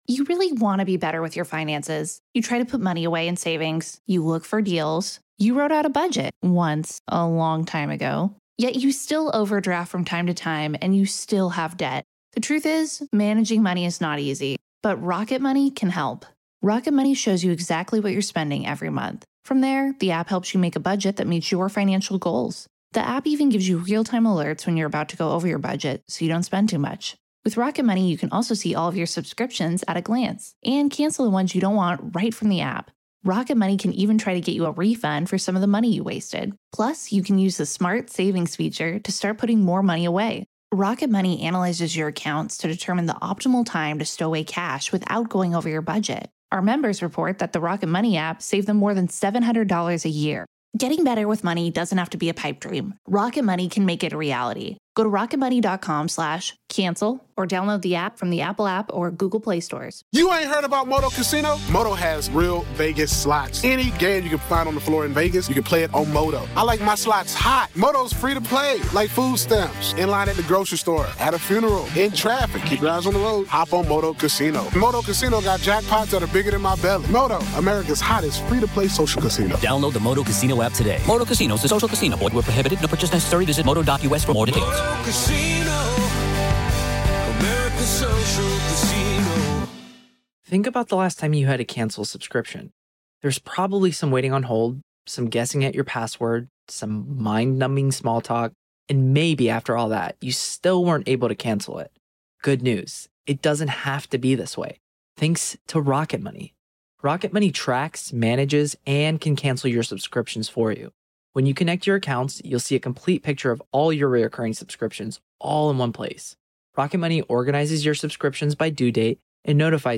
Comedy, News, News Commentary, Politics